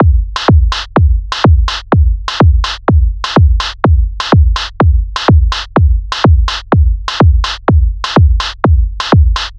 1. Tap out the bass drum and snare drum part
At first the note might seem like an 8th note pattern, but it is actually a repeated syncopated pattern. The audio sample isolates the drum part, which repeats throughout the tune.
Basic Rhythm
Basic-Rhythm.mp3